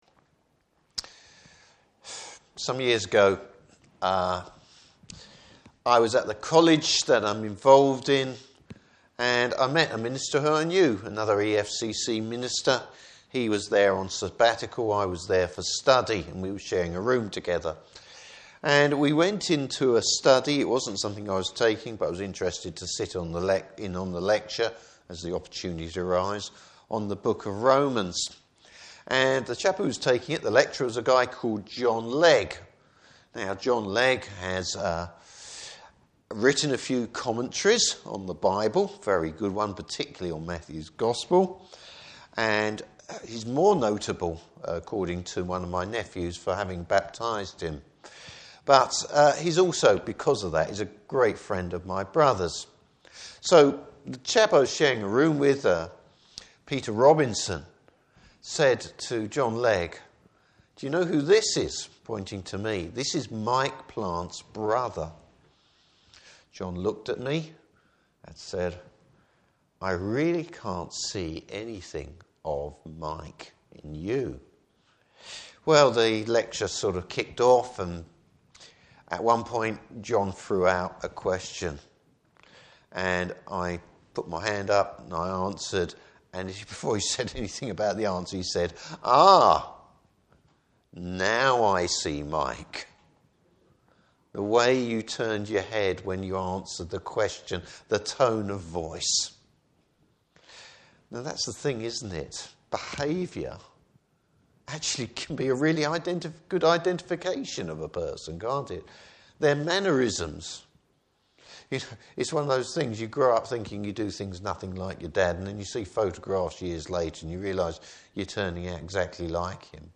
Service Type: Evening Service Bible Text: 1 John 5:1-12.